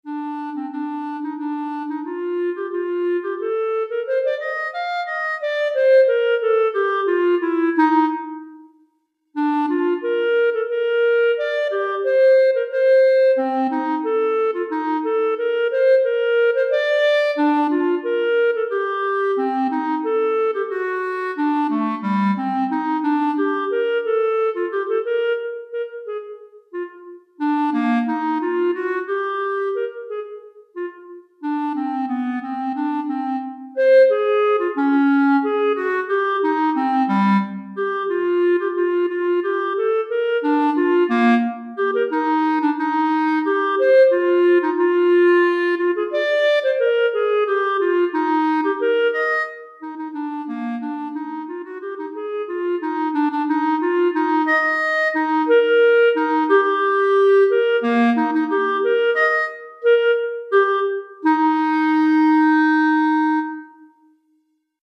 Etude pour Clarinette - Clarinette Solo en Sib